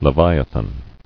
[le·vi·a·than]